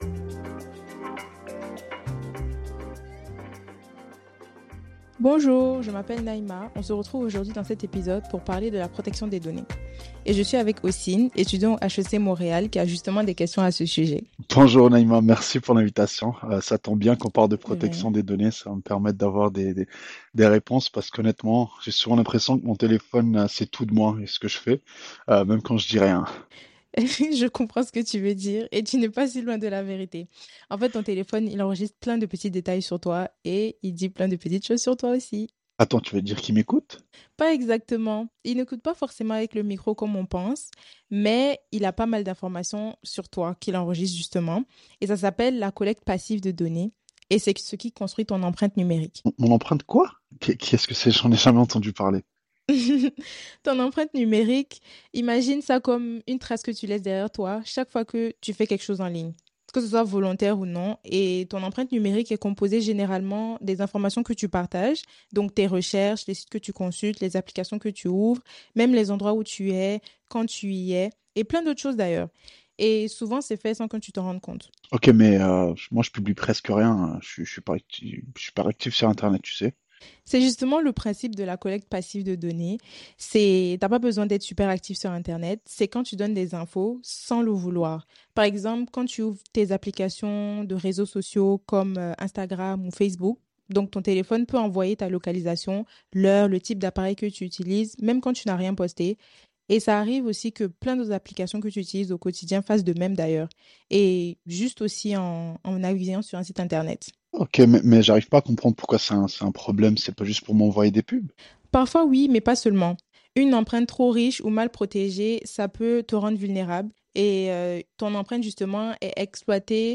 Écoutez notre podcast où deux utilisateurs échangent autour des enjeux de la collecte passive de données et des bonnes pratiques à adopter.